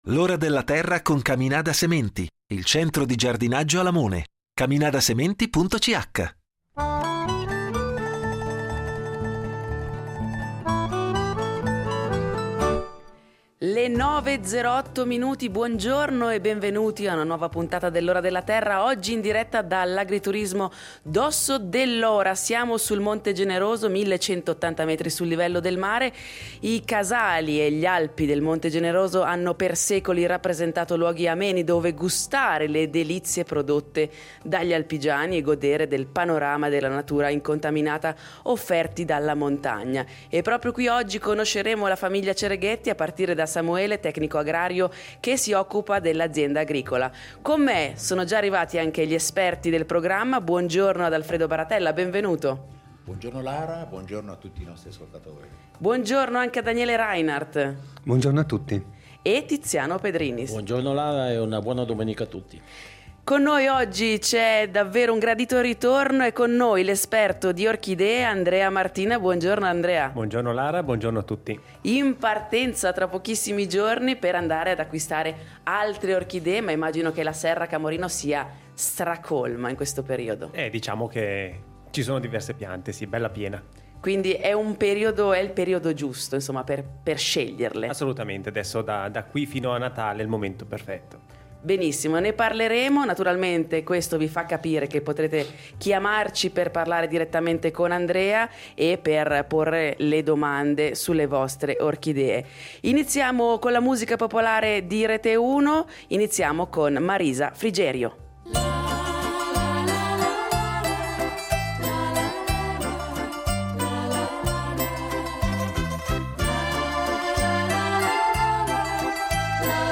In questa nuova puntata de L’Ora della Terra, saremo diretta dall’ Agriturismo Dosso dell’Ora che si trova sul Monte Generoso, a 1100 metri sul livello del mare.
Non mancheranno gli esperti classici del programma per rispondere alle domande del pubblico in diretta.